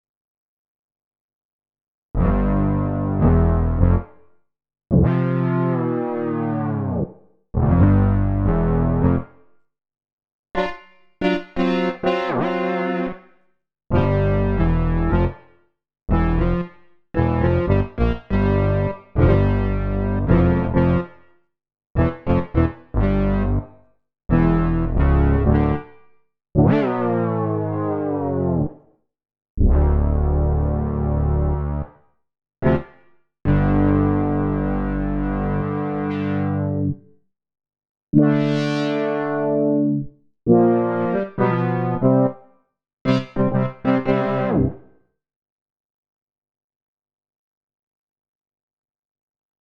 Here's a version of this Fusion song: